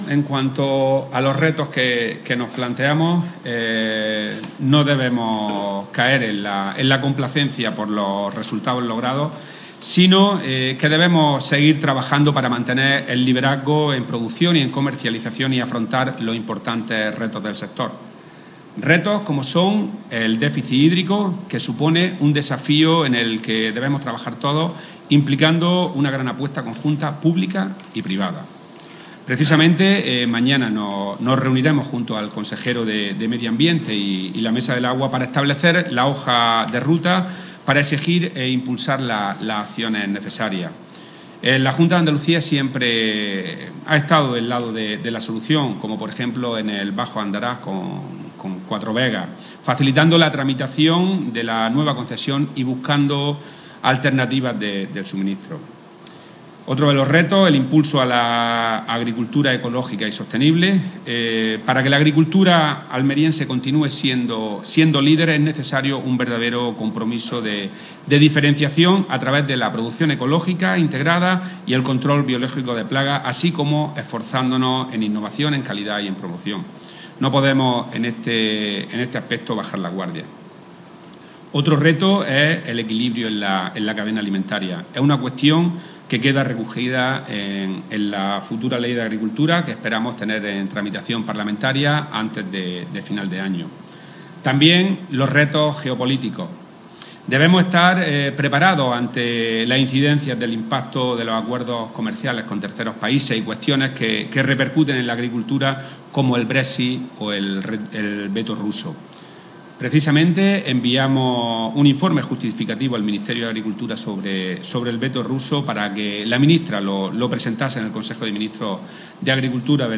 Declaraciones de Rodrigo Sánchez sobre los retos del sector hortofrutícola de Almería